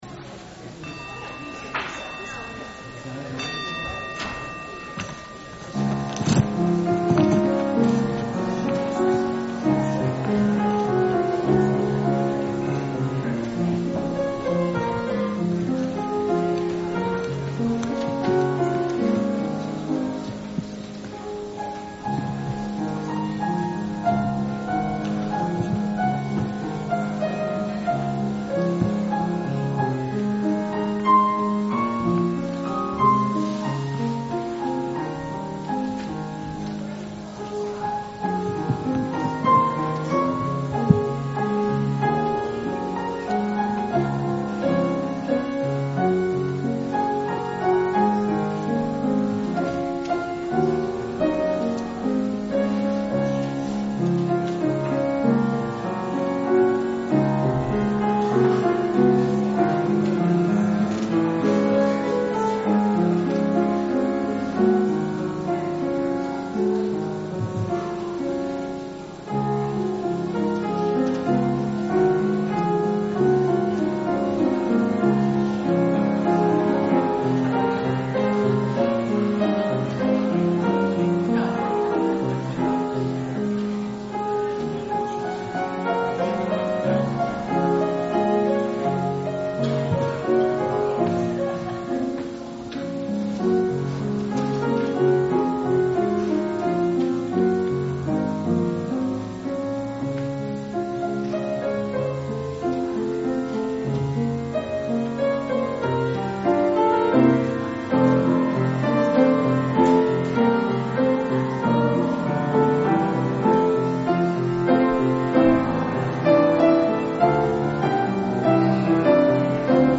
Recordings of Past Services | First Parish Church of Stow & Acton